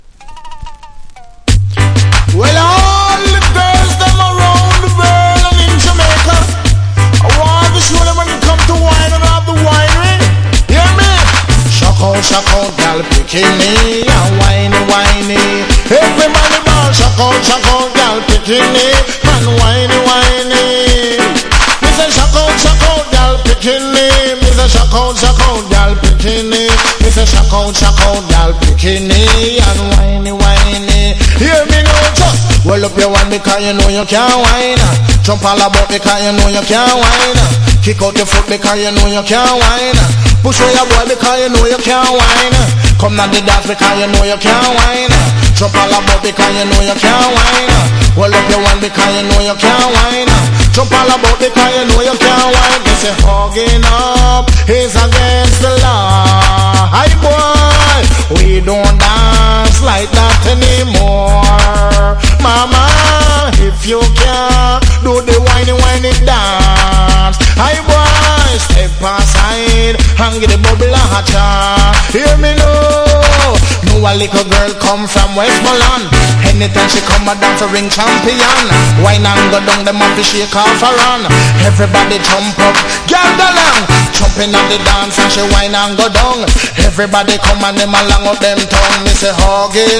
1. REGGAE >
# DANCE HALL